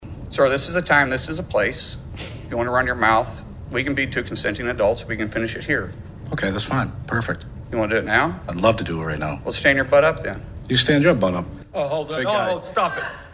CLICK HERE to listen to commentary from Senator Mark Wayne Mullin.